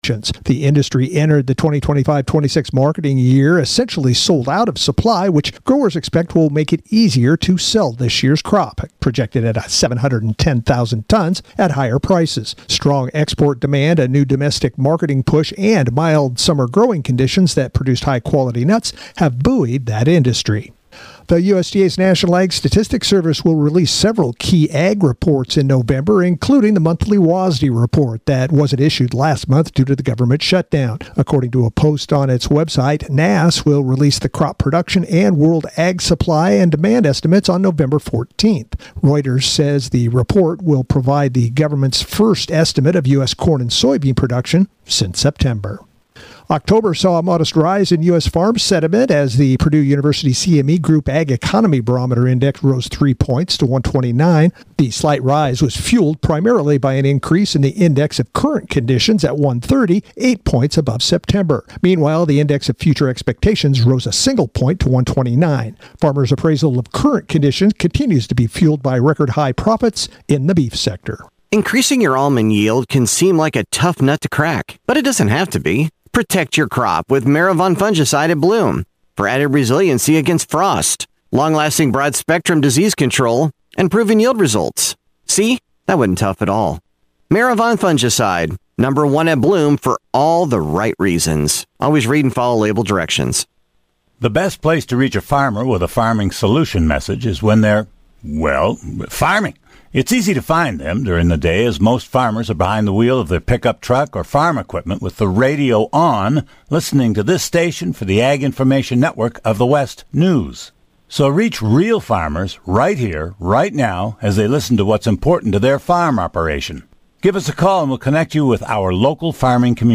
Tune in to the longest running talk show on the Central Coast.